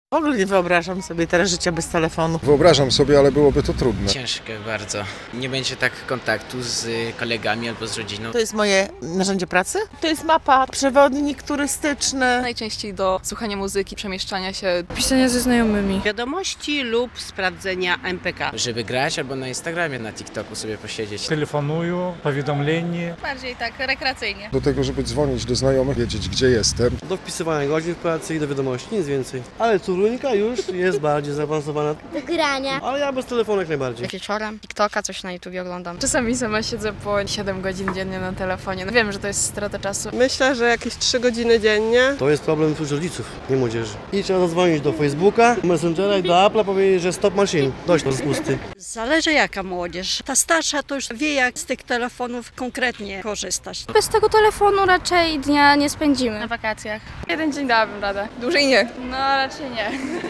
Zapytaliśmy białostoczan, czy są w stanie przyjąć takie wyzwanie.
Młodzi przechodnie z rozbrajającą szczerością deklarowali, że nie potrafią wyobrazić sobie dnia bez telefonu.